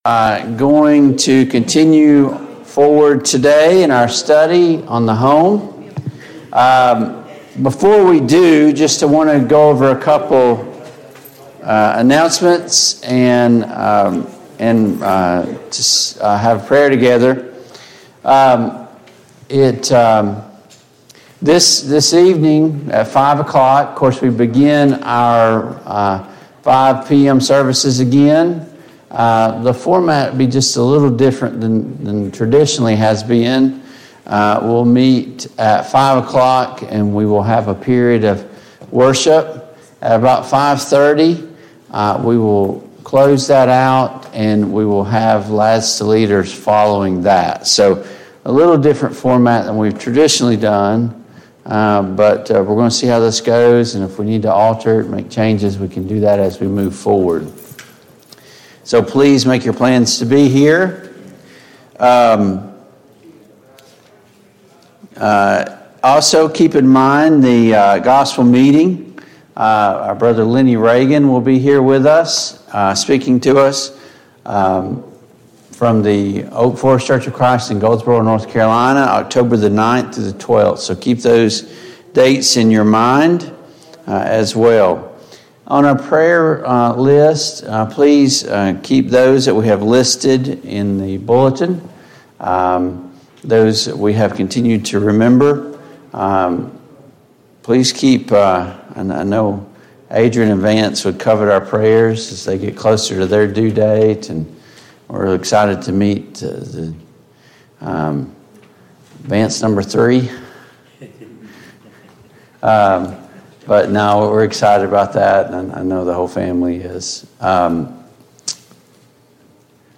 Home-Builders Family Study Passage: Genesis 1:26-28, 1 Peter 3:20, Genesis 2:18-25, Matthew 19:3-6 Service Type: Sunday Morning Bible Class